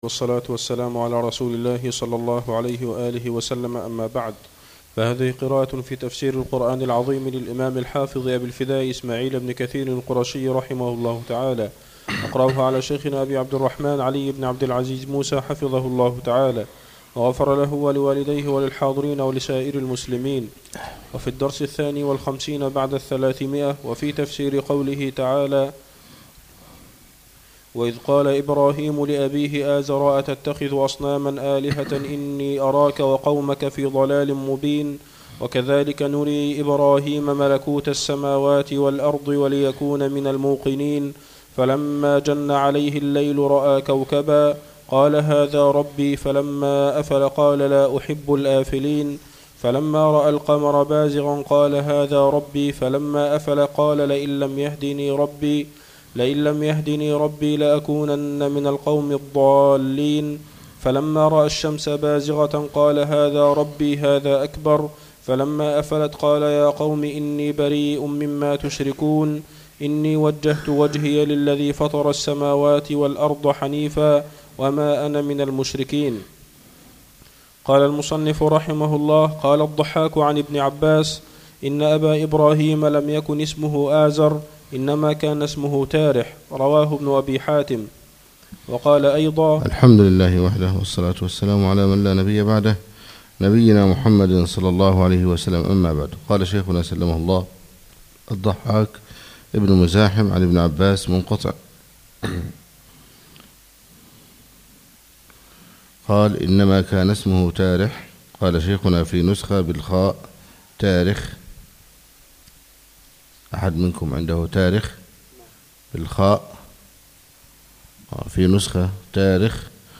الدرس الثاني والخمسون بعد الثلاثمئه